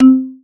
Marimba_C4_22k.wav